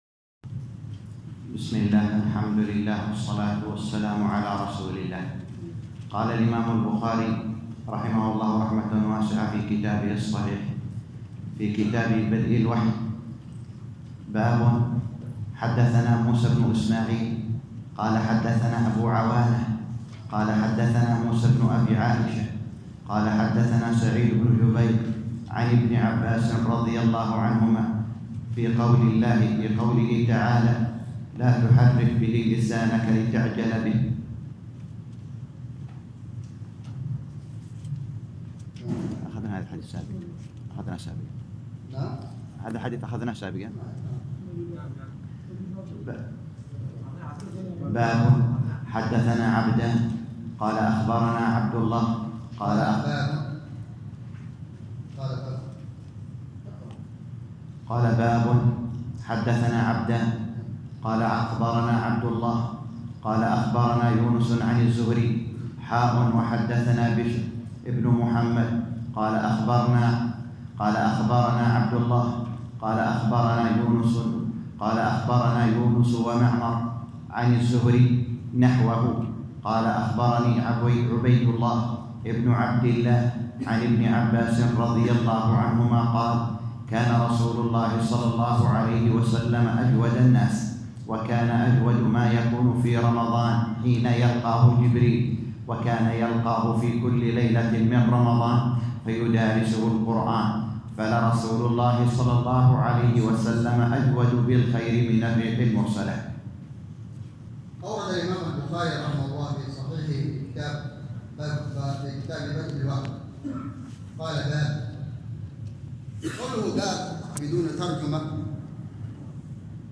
الدرس الرابع - شرح كتاب صحيح البخاري كتاب بدء الوحي _ 4